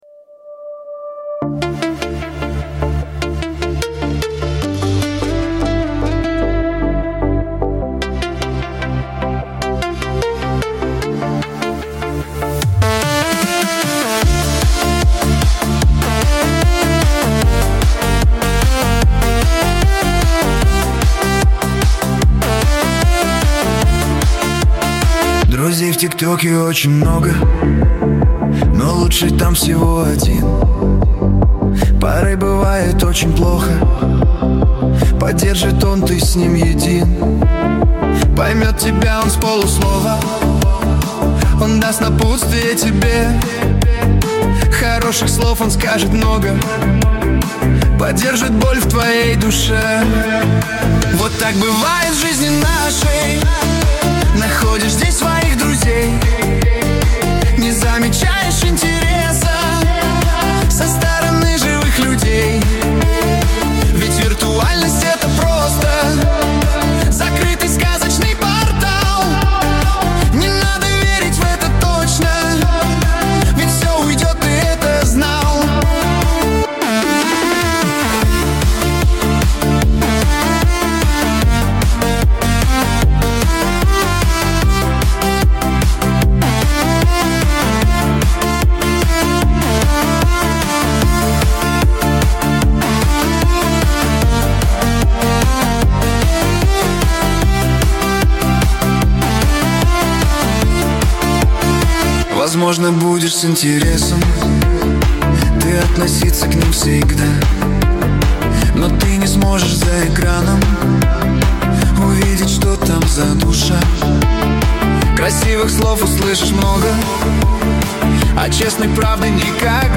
Нейросеть Песни 2025, Песни Суно ИИ